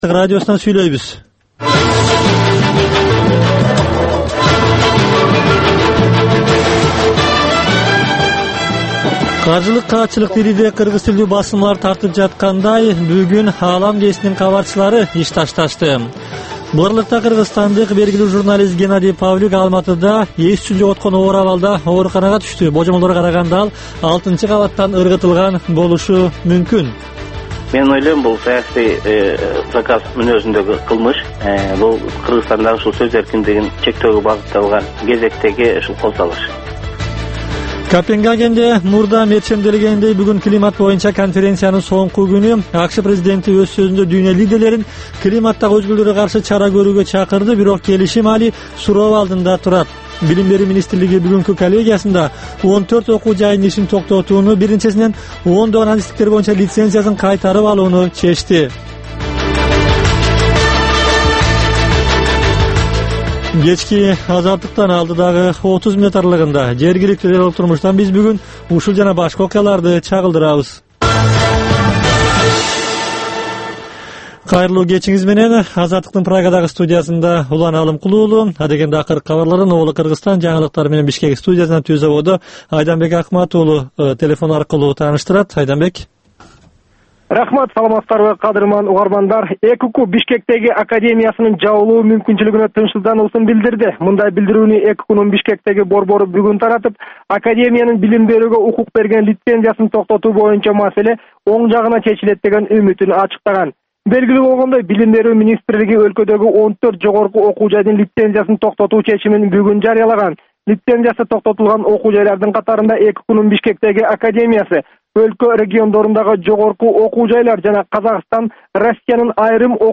Бул кечки үналгы берүү жергиликтүү жана эл аралык кабарлардан, репортаж, маек, баян жана башка берүүлөрдөн турат. "Азаттык үналгысынын" бул кечки берүүсү ар күнү Бишкек убактысы боюнча саат 21:00ден 21:30га чейин обого түз чыгат.